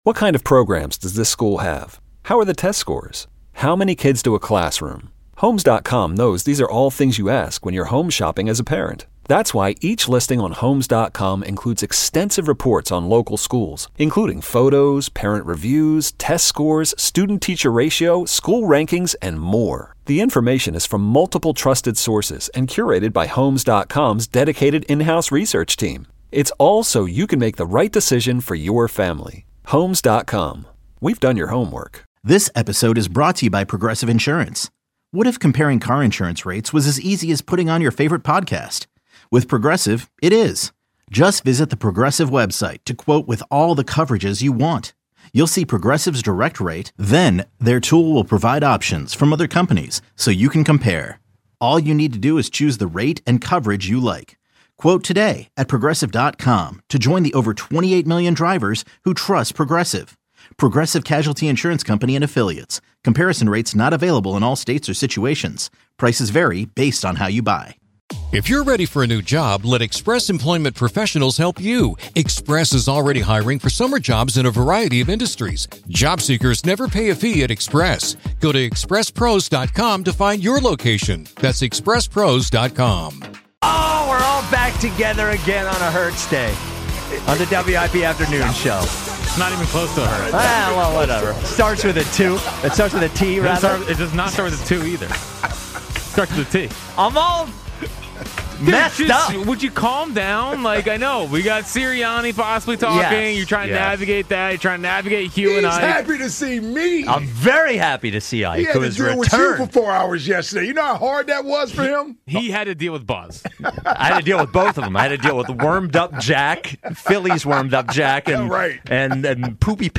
Nick Sirianni speaks to the media following his extension